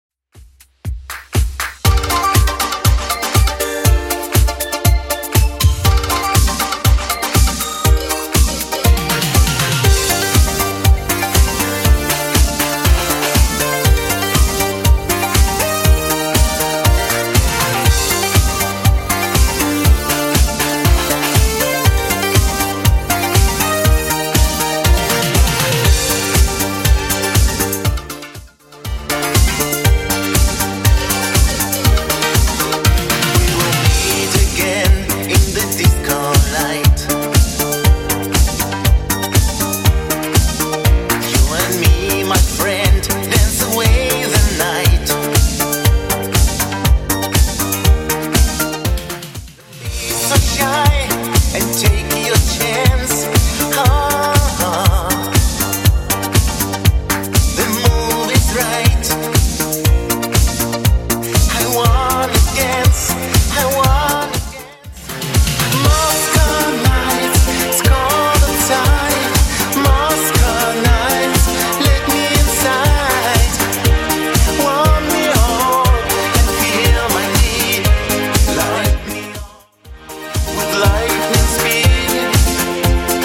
Genre: 80's Version: Clean BPM: 150